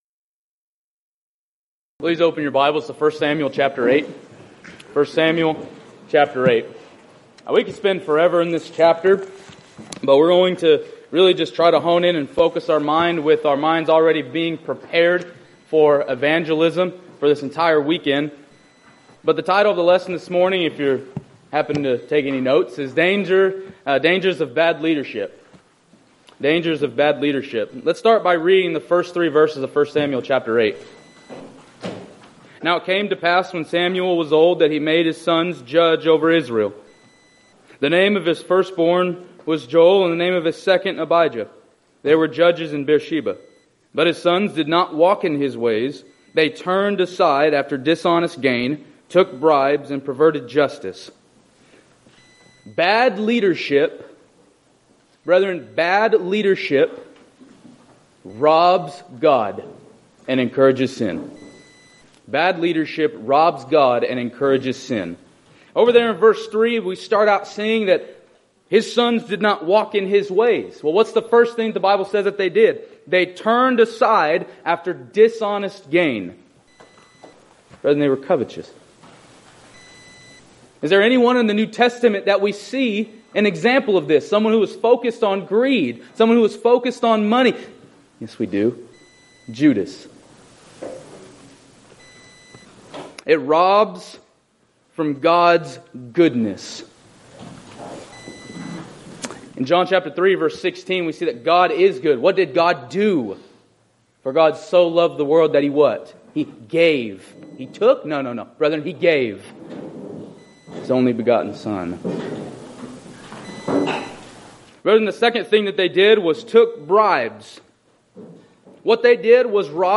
Title: SWSBS Chapel Saturday
Event: 5th Annual Arise Workshop